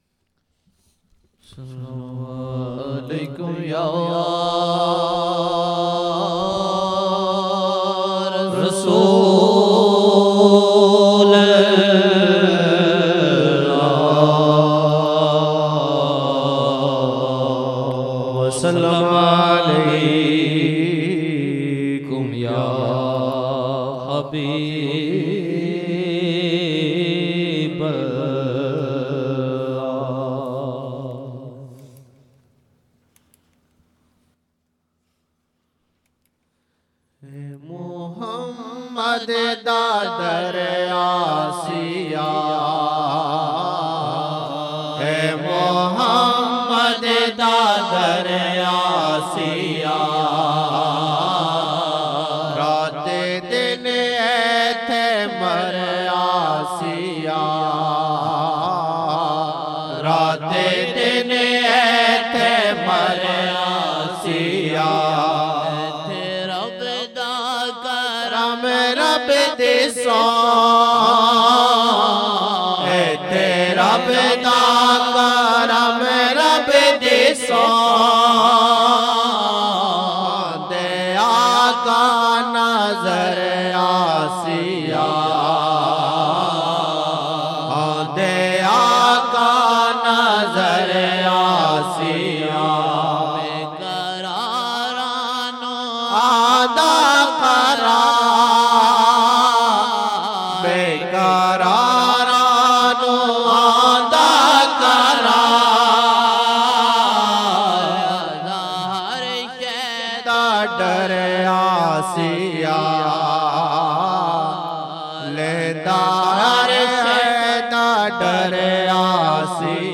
Ae Muhammad da dar aasiya, raat din aithay mar aasiya 2007-01-07 Esha 07 Jan 2007 Old Naat Shareef